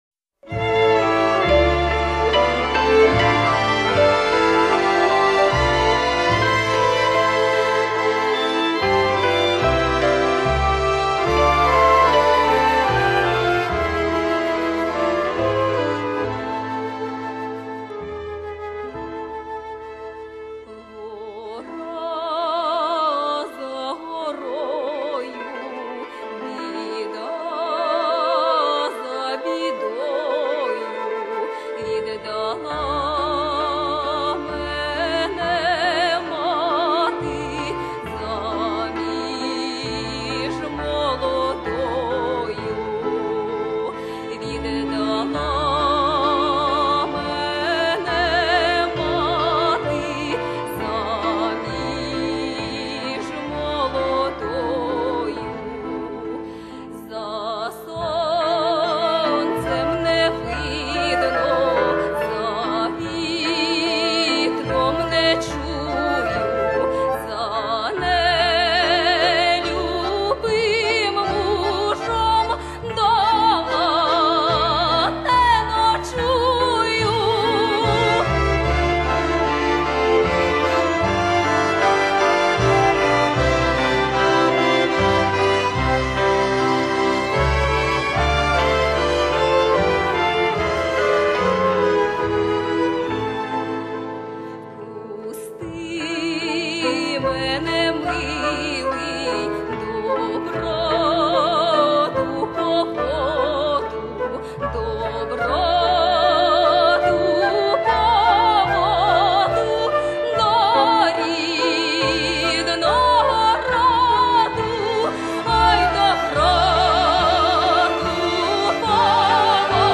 » - Ensemble ukrainien de musique traditionnelle